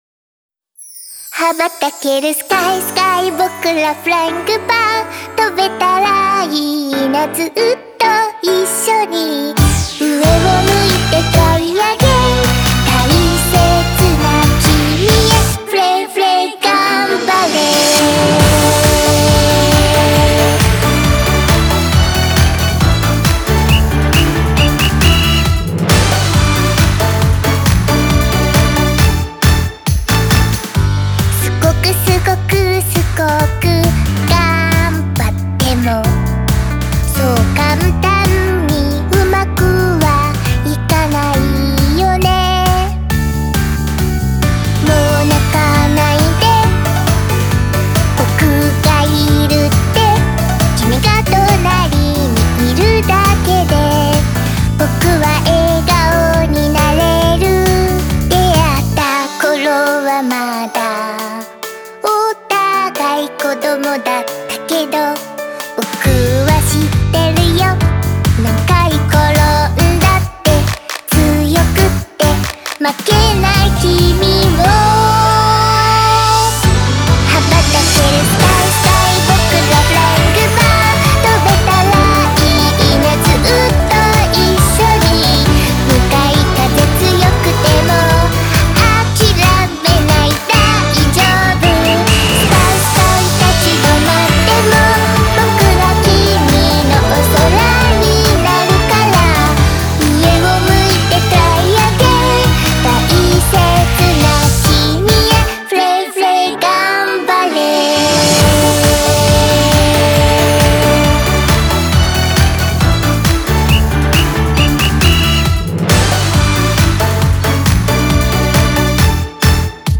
شاد و انگیزشی